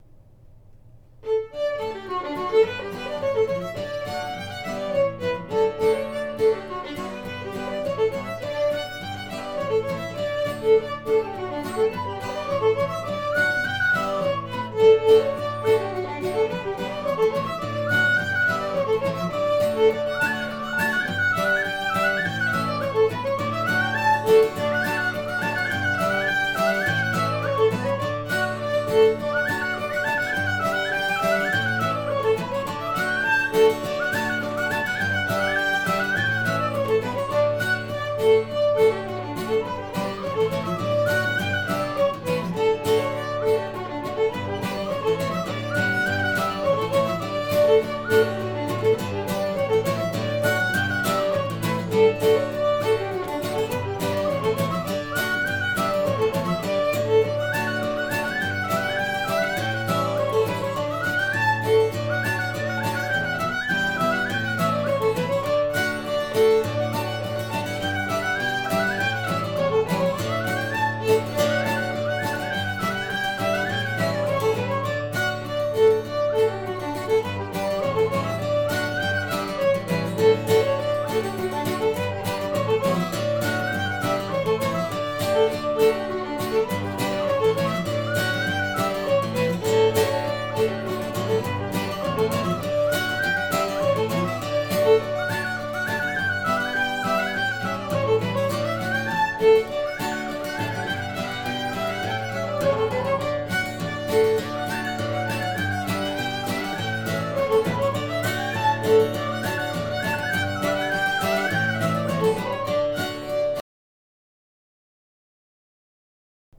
(Rant)
The "rant" referenced in the title of the tune is a type of Scottish dance similar to the Irish polka.
Sior Og